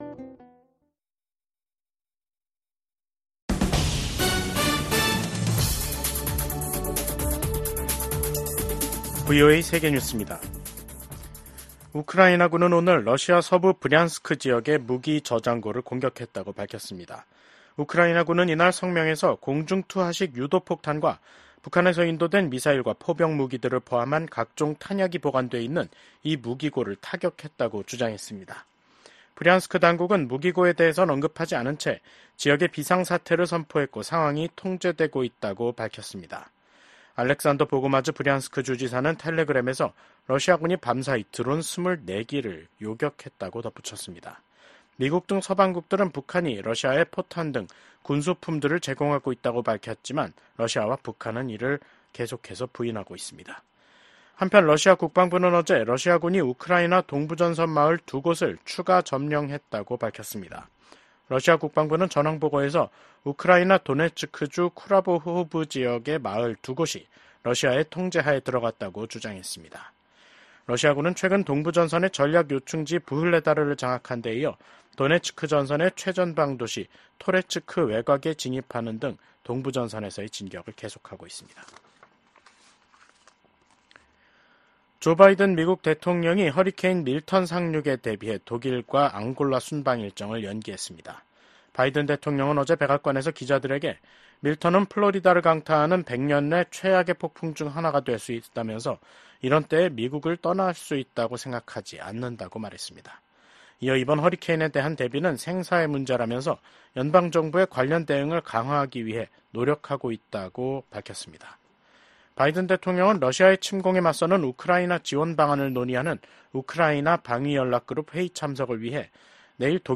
VOA 한국어 간판 뉴스 프로그램 '뉴스 투데이', 2024년 10월 9일 2부 방송입니다. 북한은 한국과 연결되는 도로와 철도를 끊고 한국과의 국경을 영구 차단하는 공사를 진행한다고 선언했습니다. 군축과 국제안보를 담당하는 유엔총회 제1위원회에서 북한의 대량살상무기 개발과 북러 군사협력에 대한 규탄이 이어지고 있습니다. 북한이 우크라이나 도네츠크 지역에 인력을 파견했다는 보도와 관련해 미 국방부는 북한의 대러 지원 움직임을 주시하고 있다고 강조했습니다.